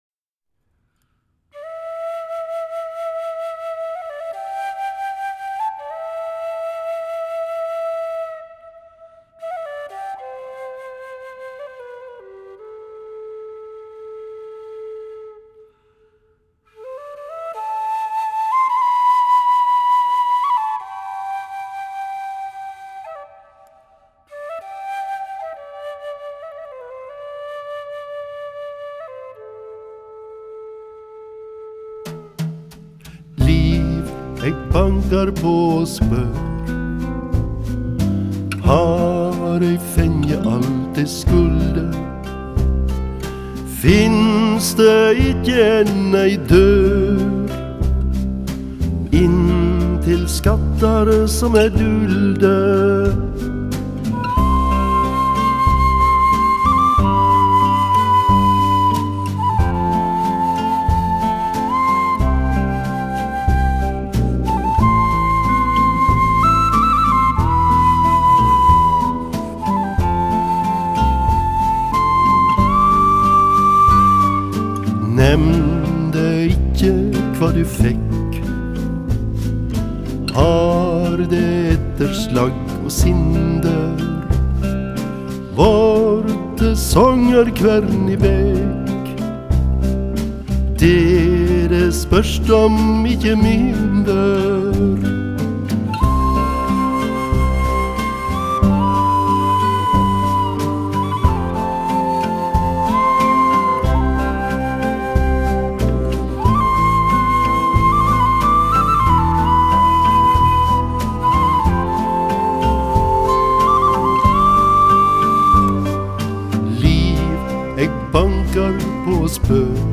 全碟音效靚絕通透，結像力和空間動態更是無與倫比，整體而言，可說是一張唱作俱佳的精心之作。